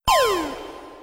TossPokeball.wav